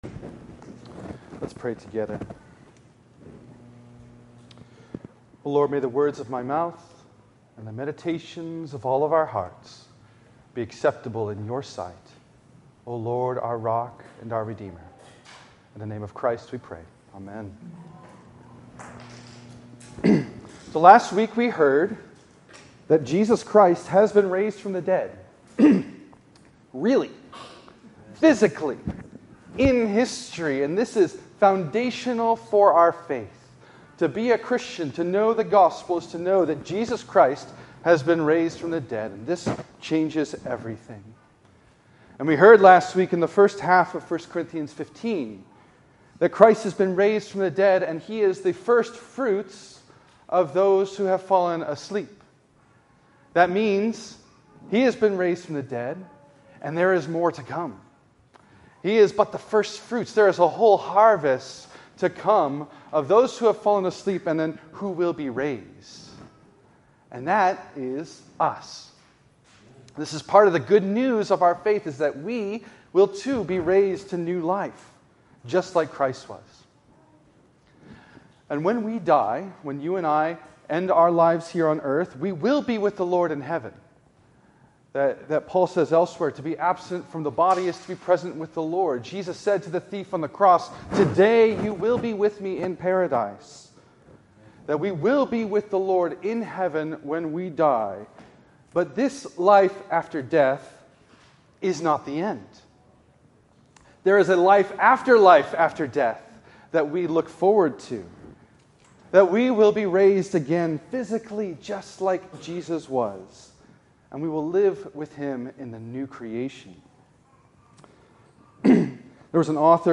In this sermon on the 6th Sunday of Epiphany